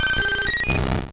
Cri d'Aquali dans Pokémon Rouge et Bleu.